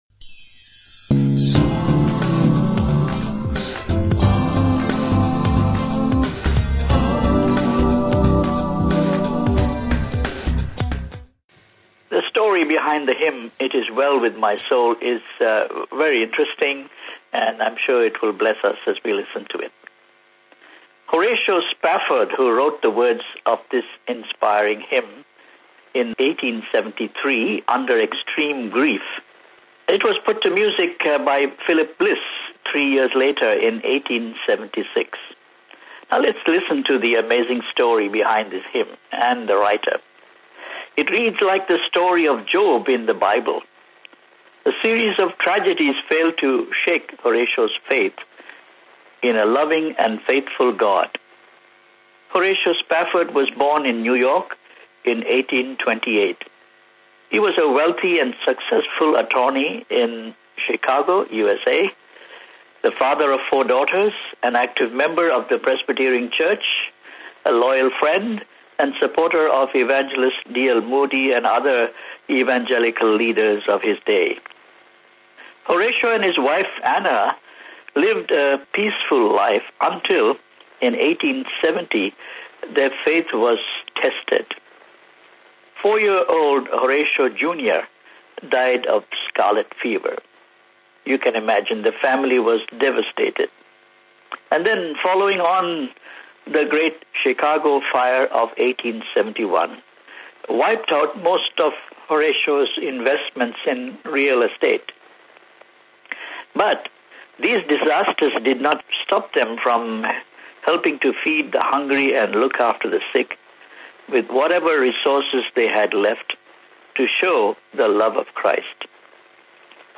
The events that gave rise to the hymn and Spafford’s response to them exemplifies the comfort and assurance that comes via faith in Christ.  Hear the story (and the hymn) for yourself.